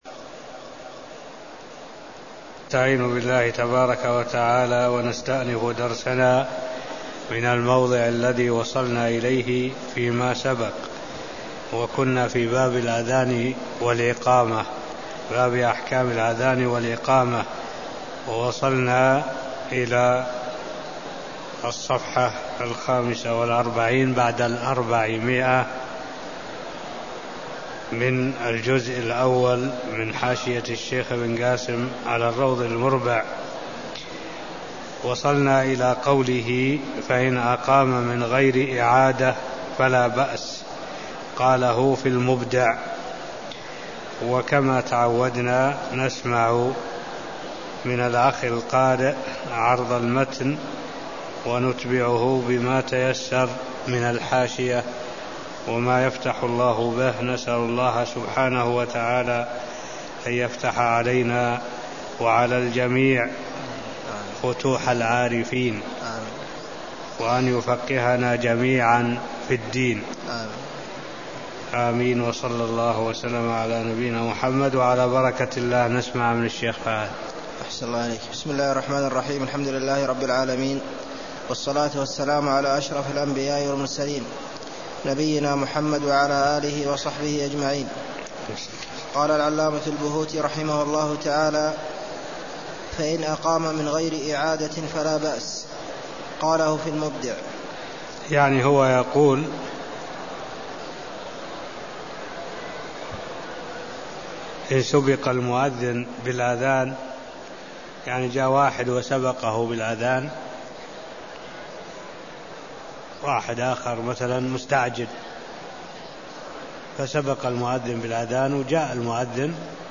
المكان: المسجد النبوي الشيخ: معالي الشيخ الدكتور صالح بن عبد الله العبود معالي الشيخ الدكتور صالح بن عبد الله العبود باب-الأذان والإقامه (0008)